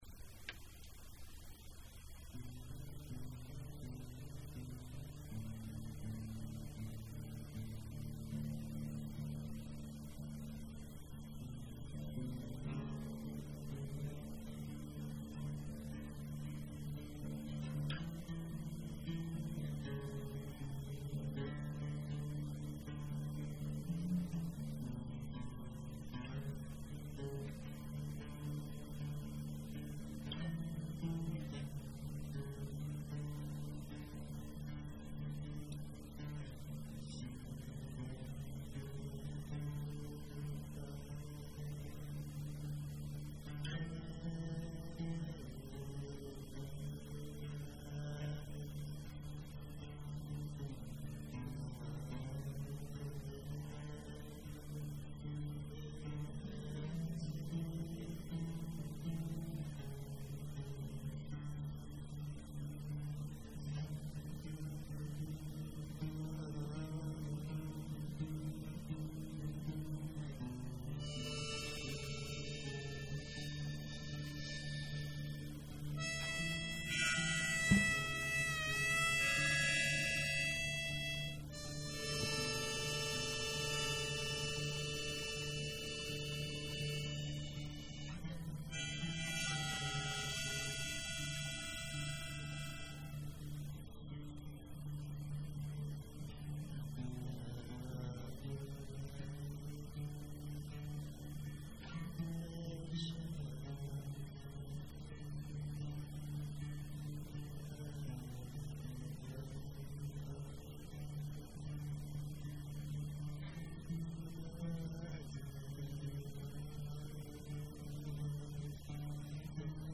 A song that is just....really trippy.